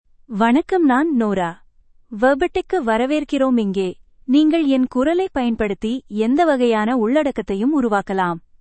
Nora — Female Tamil AI voice
Nora is a female AI voice for Tamil (India).
Voice sample
Listen to Nora's female Tamil voice.
Nora delivers clear pronunciation with authentic India Tamil intonation, making your content sound professionally produced.